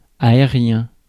Ääntäminen
Synonyymit vaporeux Ääntäminen France: IPA: [ae.ʁjɛ̃] Haettu sana löytyi näillä lähdekielillä: ranska Käännös Ääninäyte Adjektiivit 1. air UK US 2. aerial 3. airy 4. overhead US 5. overground Suku: m .